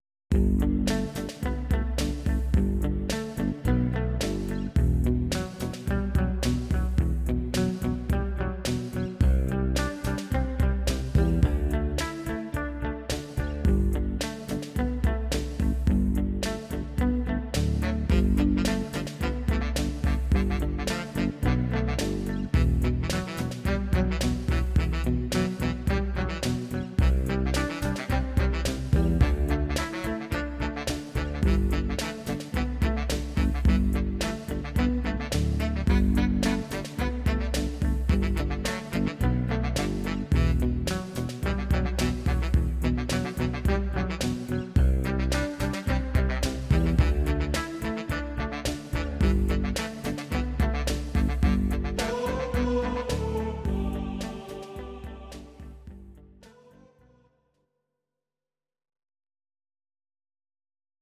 Audio Recordings based on Midi-files
Pop, Duets, 1990s